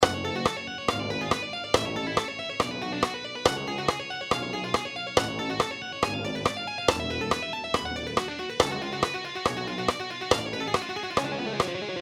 Here’s a study I’ve been working on — a fiery arpeggio sequence built around the harmonic minor scale.
Remember its on D# Tuning
harmonic-minor-arpeggio-1.mp3